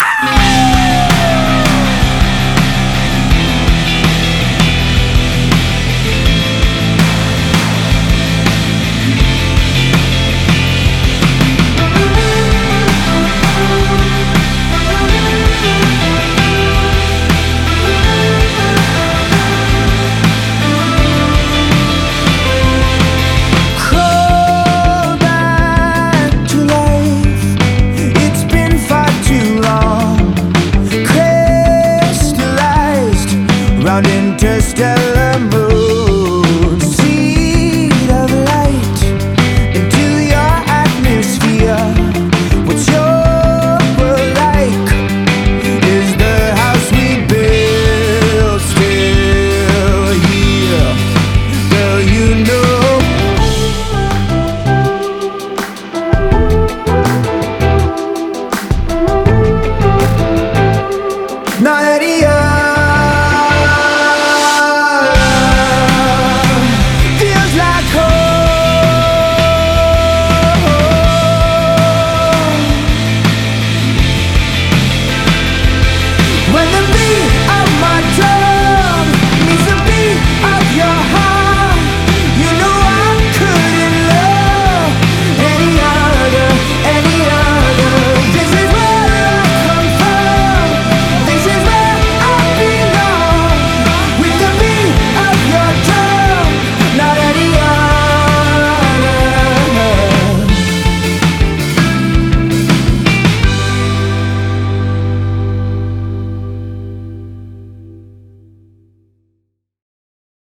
BPM122-163
Audio QualityMusic Cut